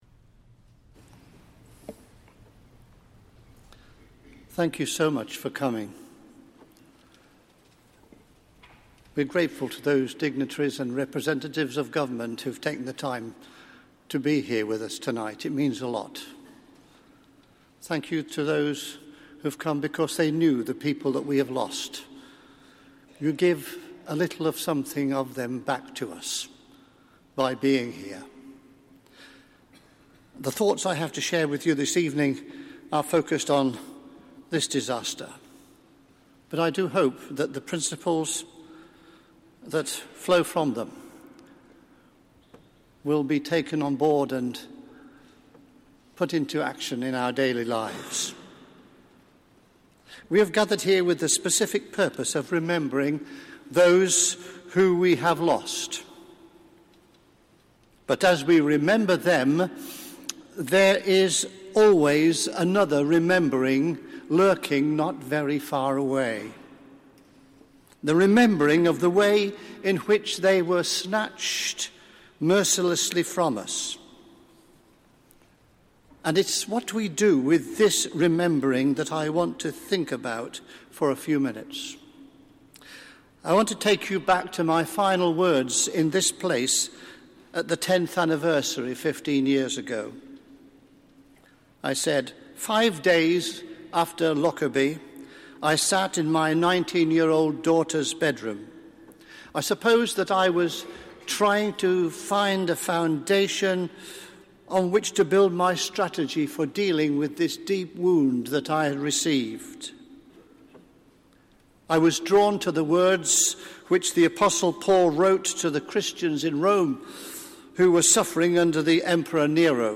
SERMON GIVEN AT A SERVICE OF REMEMBRANCE TO MARK THE 25TH ANNIVERSARY OF THE LOCKERBIE AIR DISASTER
The Right Honourable Alistair Carmichael MP, Secretary of State for Scotland and Nicola Sturgeon MSP, Deputy First Minister for Scotland attended a Service of Remembrance to mark the 25th anniversary of the Lockerbie Air Disaster at Westminster Abbey on Saturday 21st December.